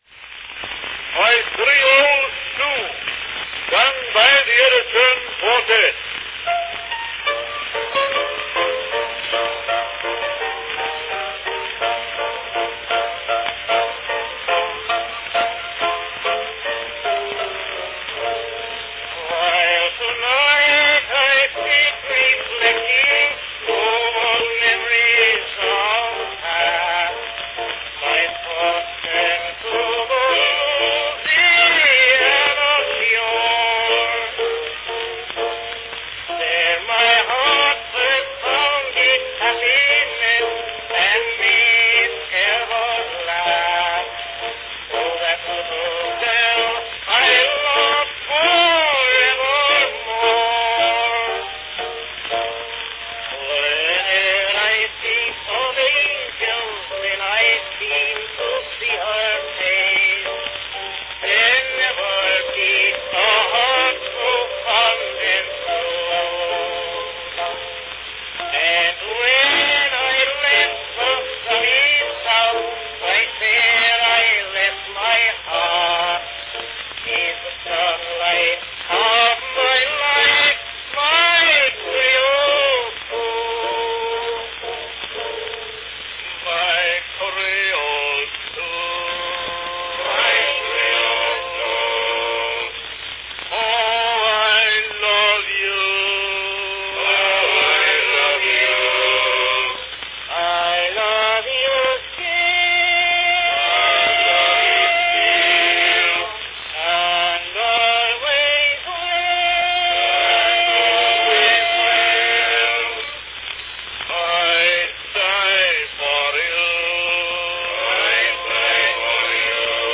Cylinder of the Month
From 1899, the Edison Quartette sings the popular ballad My Creole Sue.
Category Quartette
Performed by Edison Male Quartette
Announcement "My Creole Sue, sung by the Edison Quartette."